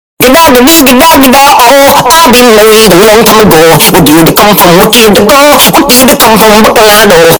Gegagedigedagedago Earrape